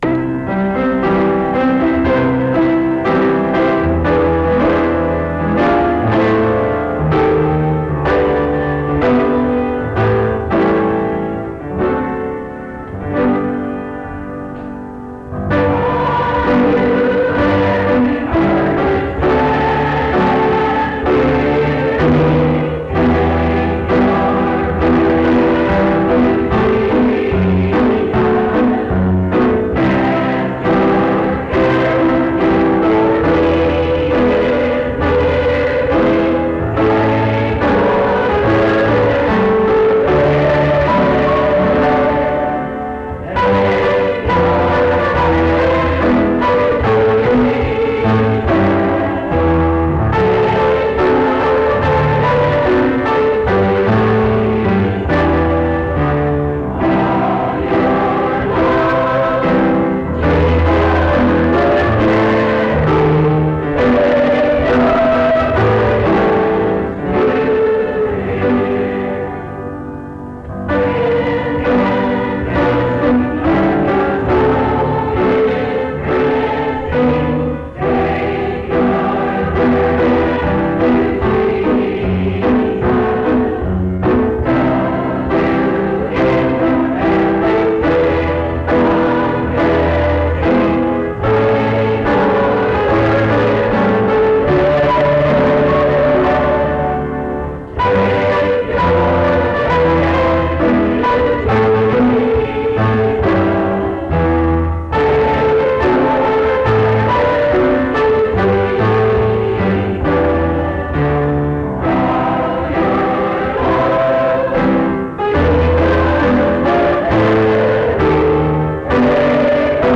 Take Your Burden to Jesus Item 7260b3b090bb5e6cde23b975cf78284b3c3d6758.mp3 Title Take Your Burden to Jesus Creator Evangel Choir Description This recording is from the Monongalia Tri-District Sing. Mount Union Methodist Church II, rural, Monongalia County, WV, track 145W.